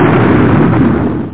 thunders.mp3